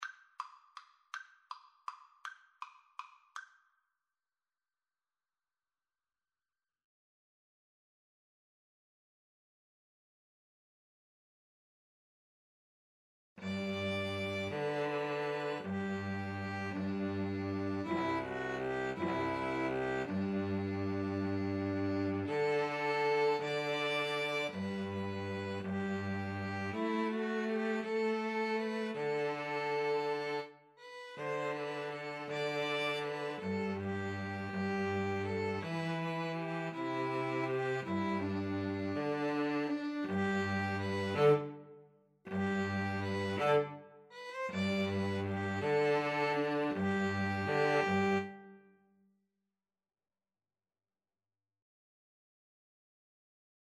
3/4 (View more 3/4 Music)
One in a bar . = c.54
Pop (View more Pop Piano Trio Music)